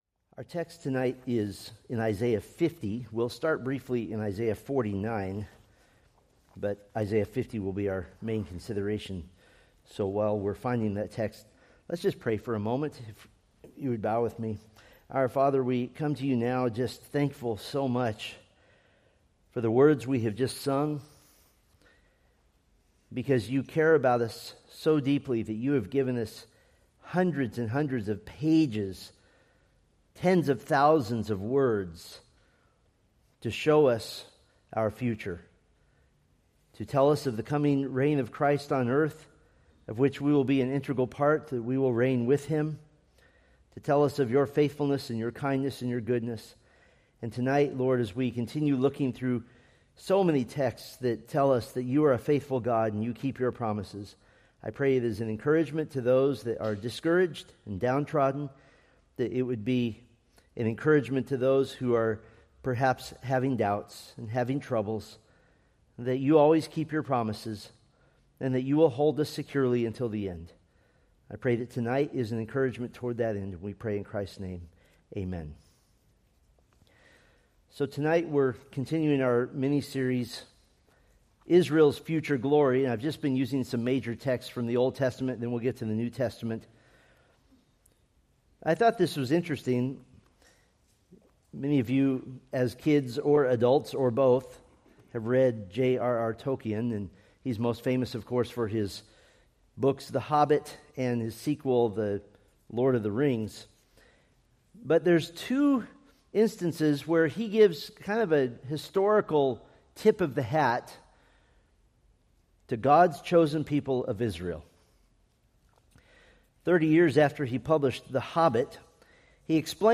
Preached November 23, 2025 from Selected Scriptures